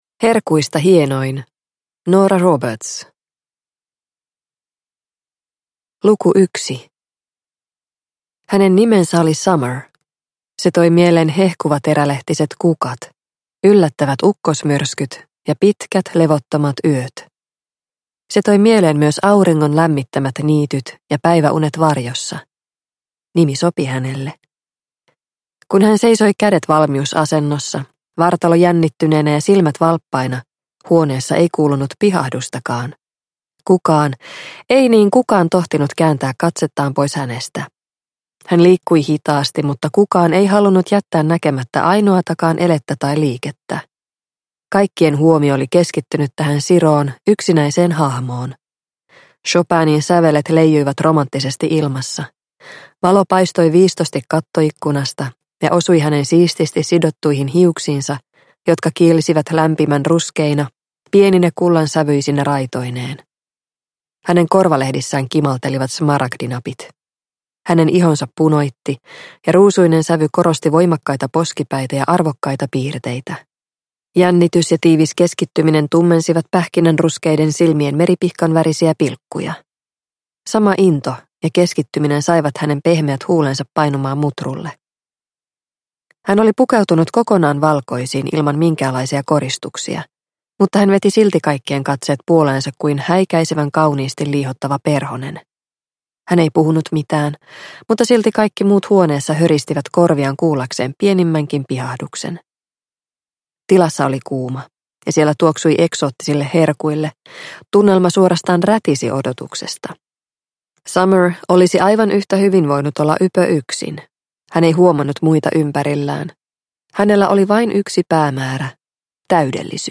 Herkuista hienoin – Ljudbok – Laddas ner